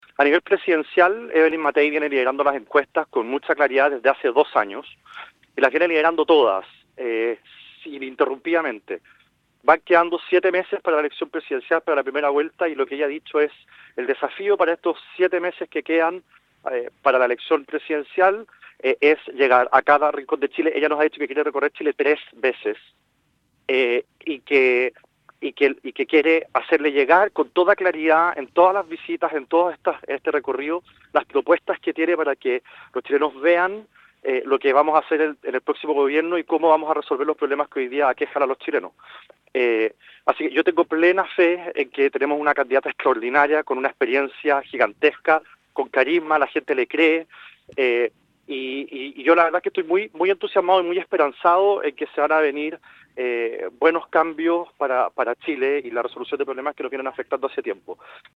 En cuanto a las perspectivas de Matthei en estas elecciones presidenciales, Ramírez recordó que la ex alcaldesa lidera las encuestas desde hace más de dos años y que espera llegar con sus propuestas a todo Chile, recorriendo el país 3 veces de aquí a noviembre.